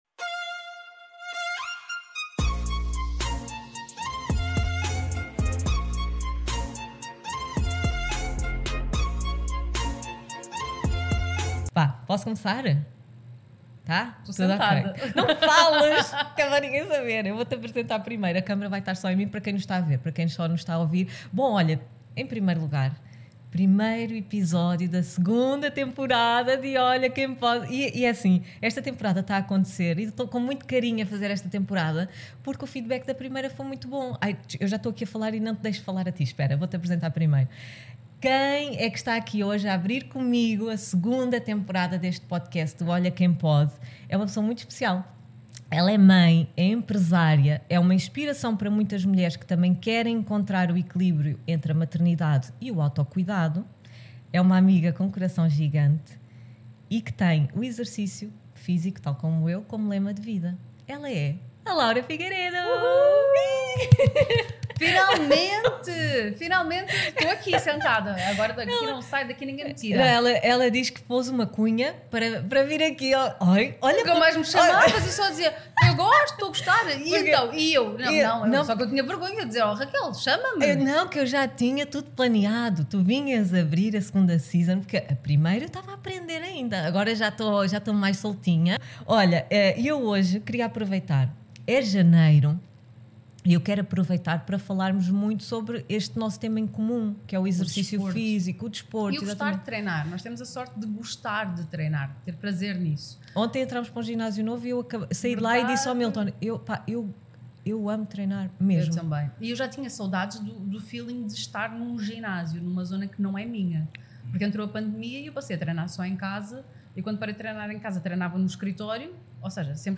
Nesta conversa falámos de exercício físico, maternidade, a nossa ida para um ginásio… enfim, uma conversa leve e cheia de boa energia para arrancar com este primeiro episódio!